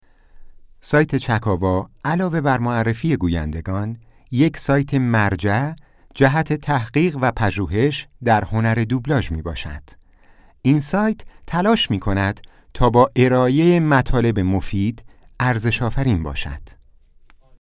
نمونه صدای مستند
mostanad.mp3